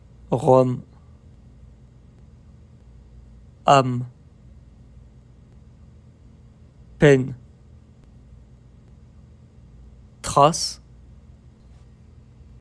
As you play each of the below audio files, listen carefully and repeat the sounds you hear in the pause between words.